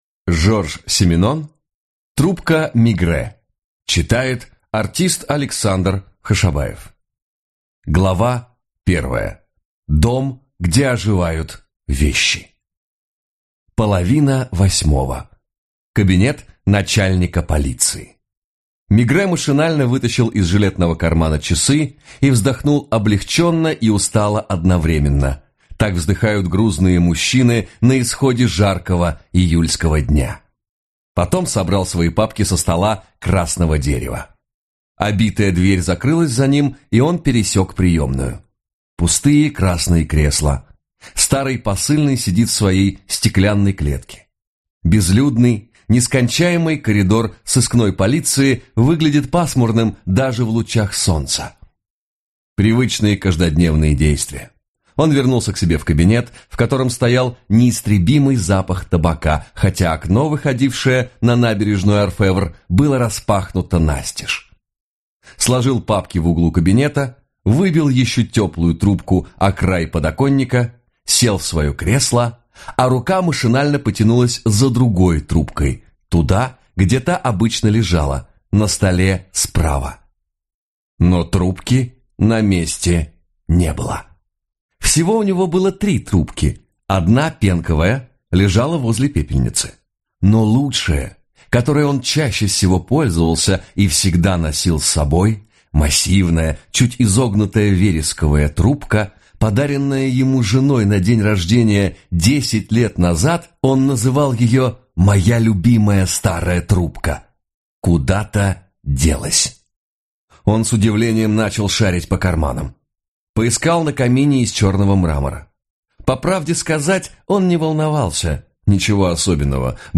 Аудиокнига Трубка Мегрэ | Библиотека аудиокниг